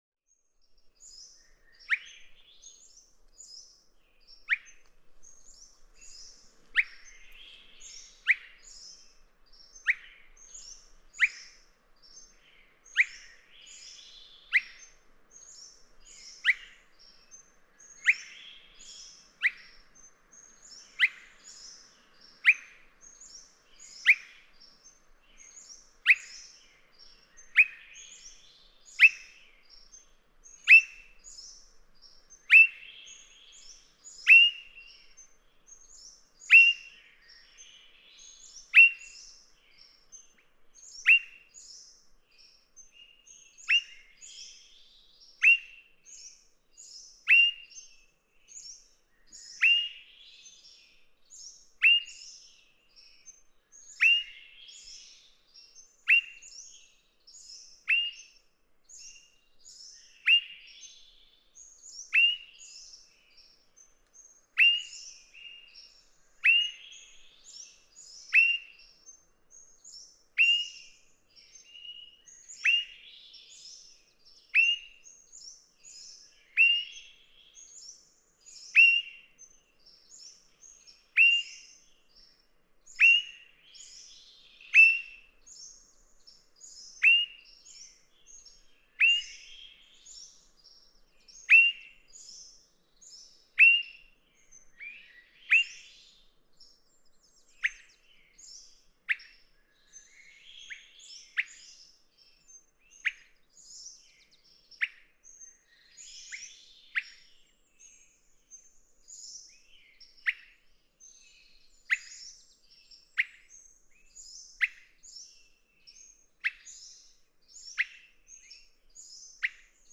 Swainson's thrush
Before contributing their songs to the dawn chorus, these thrushes awake with calls, typically matching the nuances of calls from other Swainson's thrushes in the neighborhood.
South Slough National Estuarine Research Reserve, Charleston, Oregon.